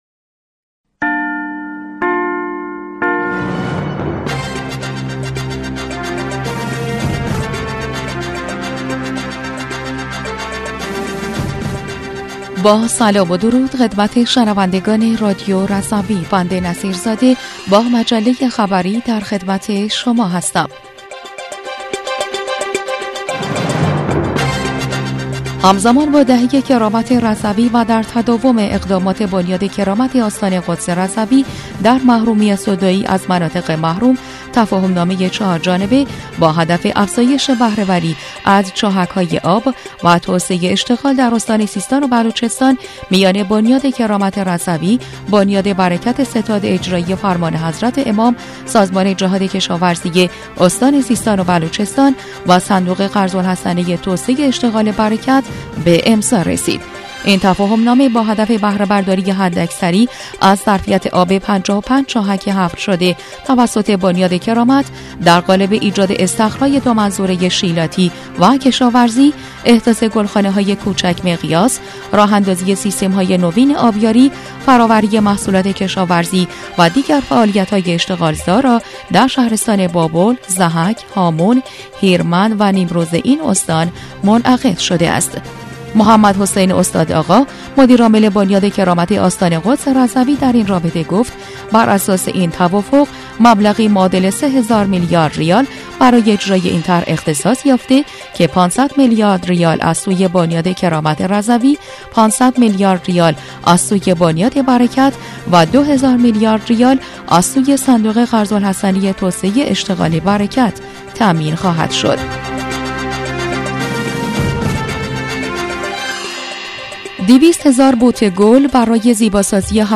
بسته خبری یکشنبه ۱۴ اردیبهشت ۱۴۰۴ رادیو رضوی؛